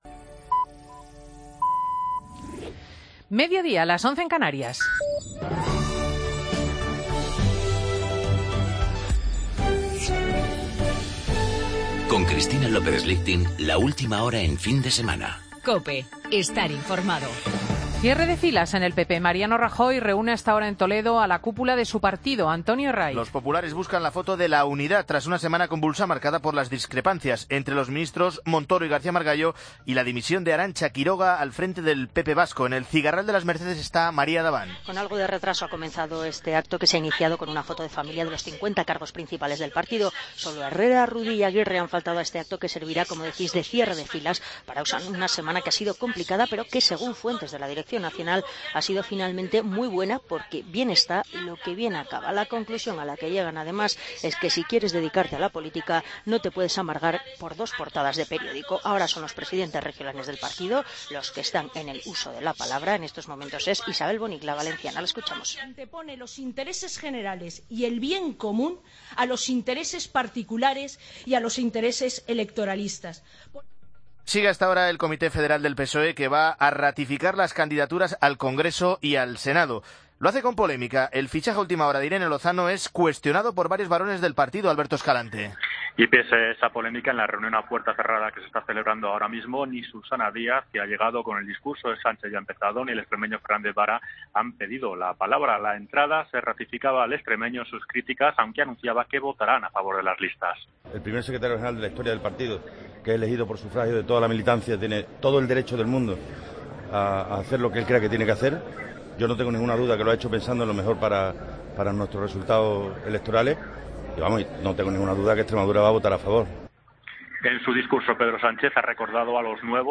Noticias de las 12.00 horas, sábado 17 de octubre de 2015